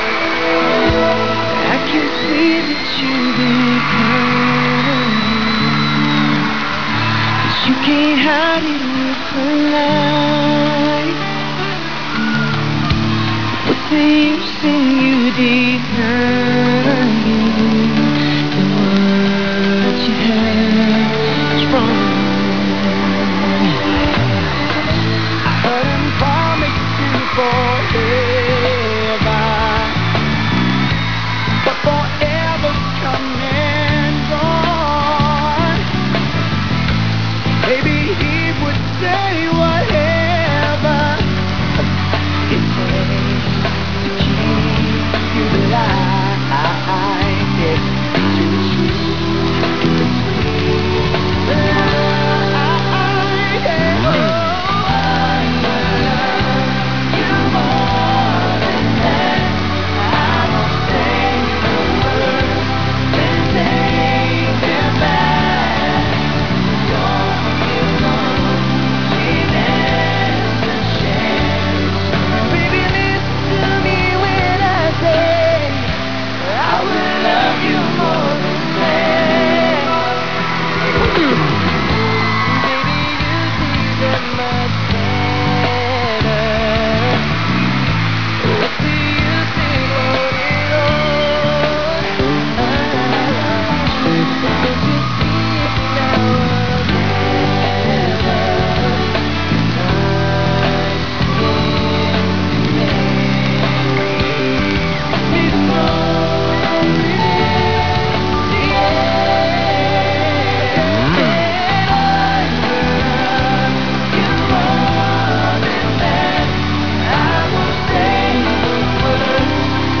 Sounds Recorded From TV Shows
Live Sounds from LA Concert -- MAY 30